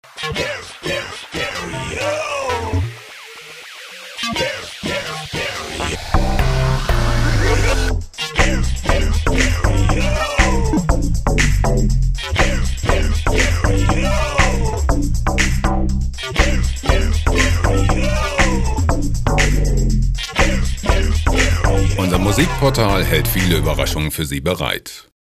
IDM Loops
Musikstil: Pop
Tempo: 120 bpm